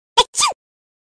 เสียงจาม ฮัดชิ่ว น่ารักๆ (เสียงข้อความ)
หมวดหมู่: เสียงเรียกเข้า